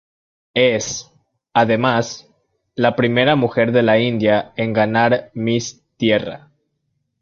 ga‧nar
Wymawiane jako (IPA)
/ɡaˈnaɾ/